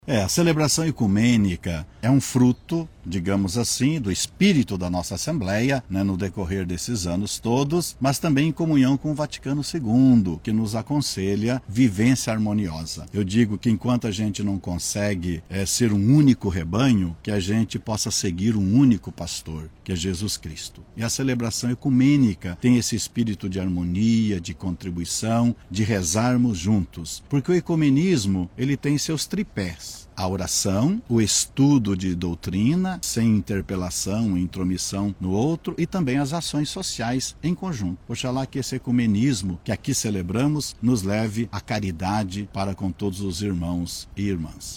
SONORA-02-DOM-MARIO.mp3